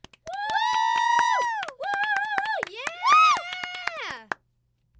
SmallGroupCheer